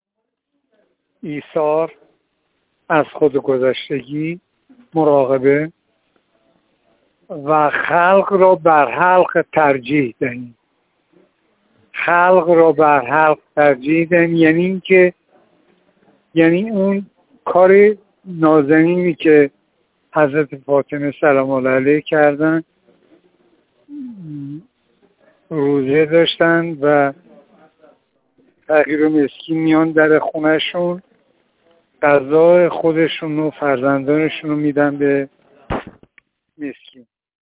حبیب‌الله صادقی، نقاش پیشکسوت انقلاب در گفت‌وگو با ایکنا بیان کرد: تقارن ماه مبارک رمضان با نوروز و میلاد طبیعت خیلی ارزشمند است.